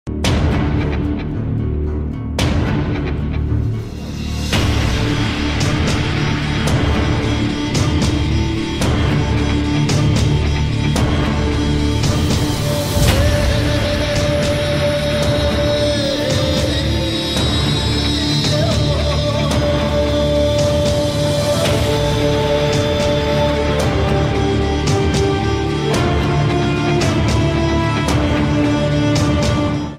Japan Ki Fast Train Jr Sound Effects Free Download